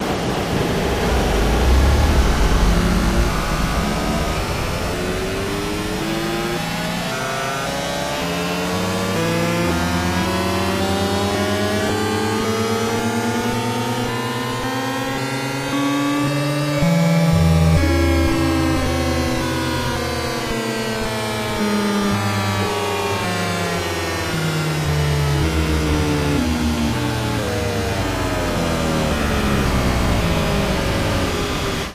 Android Analyze digital sounds with looping crescendos